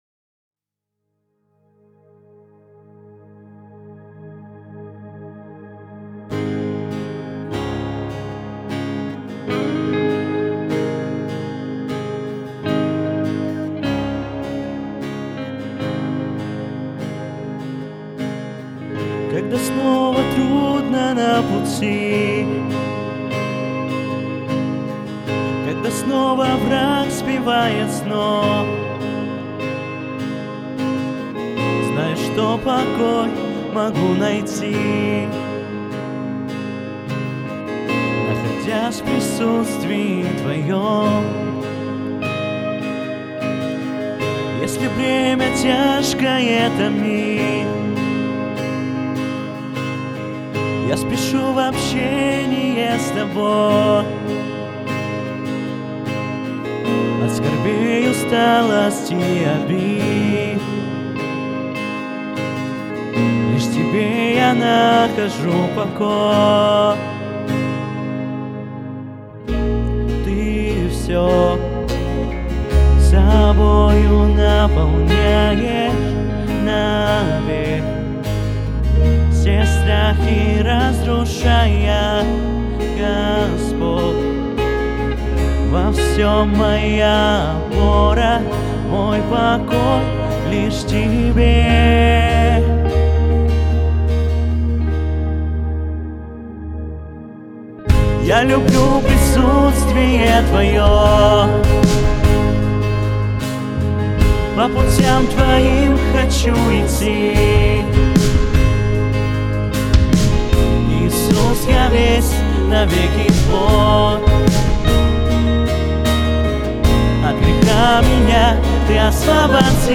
181 просмотр 104 прослушивания 8 скачиваний BPM: 76